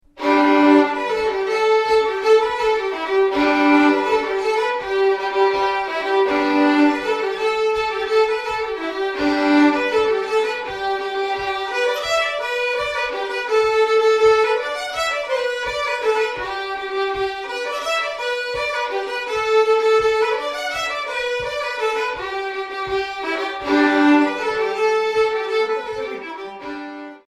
Throughout the duration of UnstFest, we will be recording some of the many events that are scheduled.
Fiddle Workshop - 10.07.09
Keen Fiddlers Being Put Through Their Paces Learning A New Tune
Fiddle Workshop Sample 2 -